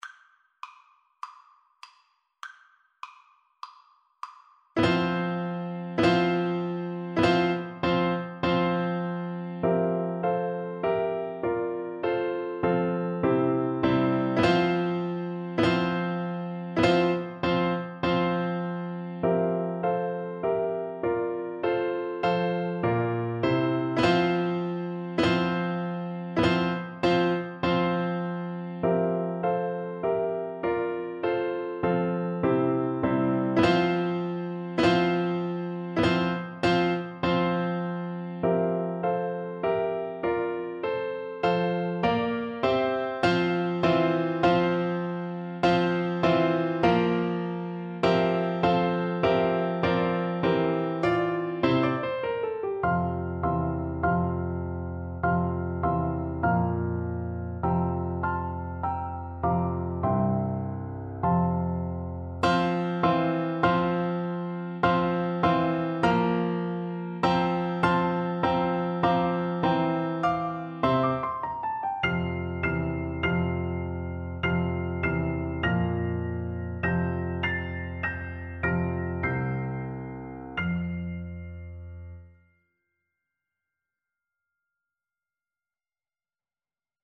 Play (or use space bar on your keyboard) Pause Music Playalong - Piano Accompaniment Playalong Band Accompaniment not yet available transpose reset tempo print settings full screen
Flute
4/4 (View more 4/4 Music)
March = c.100
F major (Sounding Pitch) (View more F major Music for Flute )
Scottish